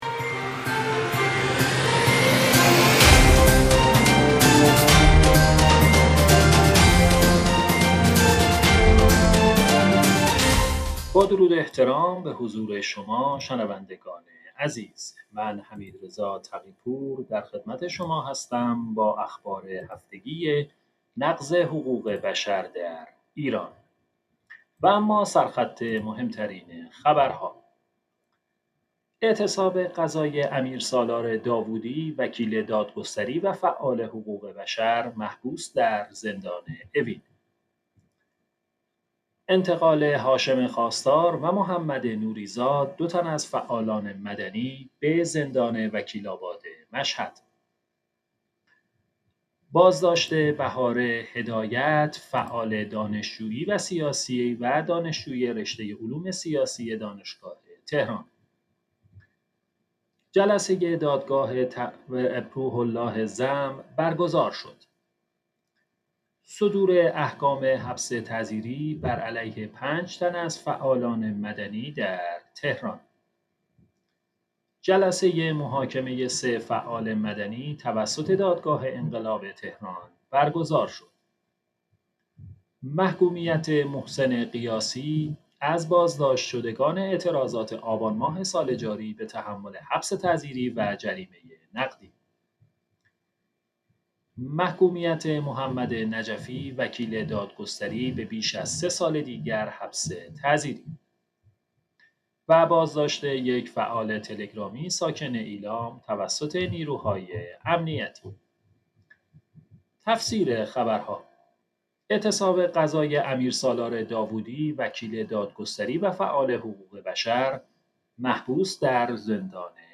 اخبار هفتگی نقض حقوق بشر در ایران